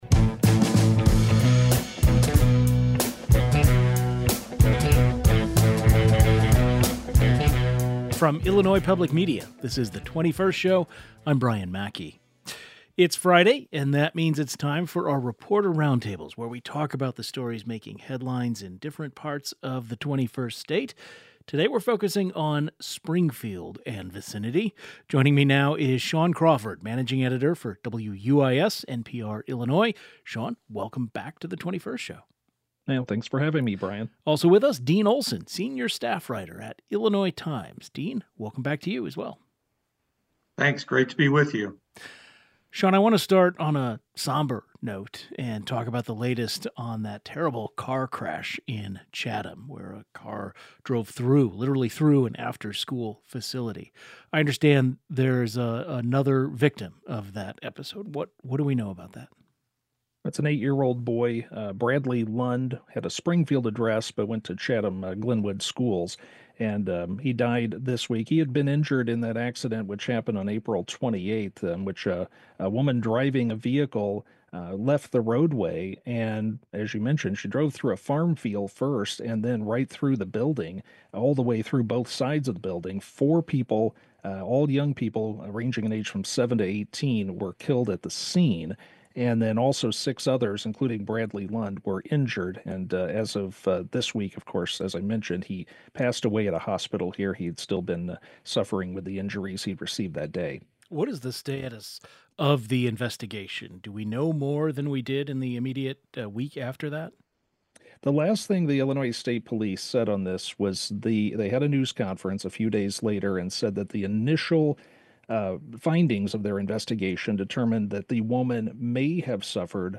Our Friday, June 5, 2025 Reporter Roundtable covering stories making headlines in Springfield, including the latest on the Chatham afterschool crash and new plans to business in downtown booming.